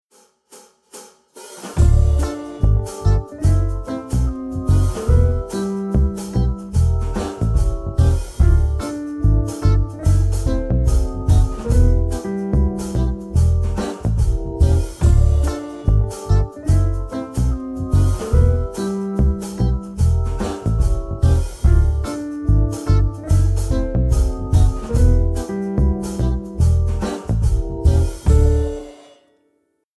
Akkordprogression med modulation og gehørsimprovisation:
Lyt efter bassen, der ofte spiller grundtonen.
Modulation: En stor sekund op eller en stor sekund ned
C instrument (demo)
Vi har valgt, at lade opgaverne modulere, ved at anvende 2-5-1 kadencen.